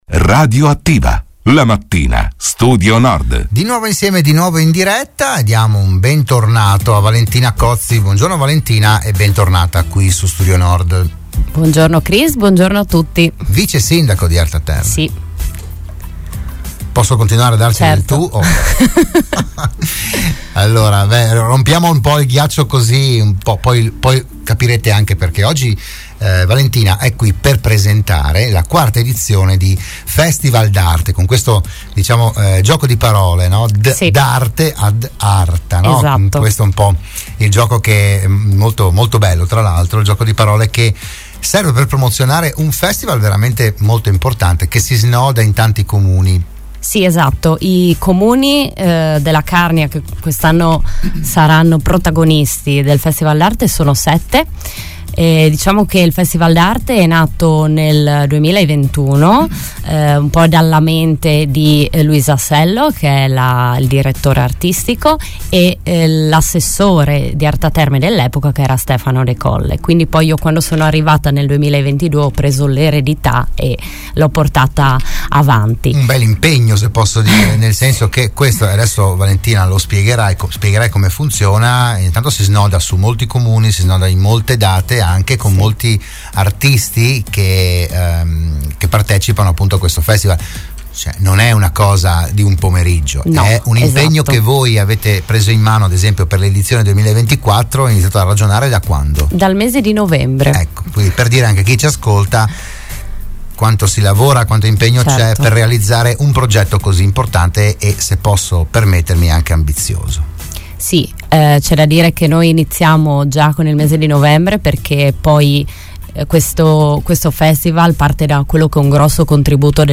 Ne ha parlato a Radio Studio Nord l'assessore comunale di Arta Terme Valentina Cozzi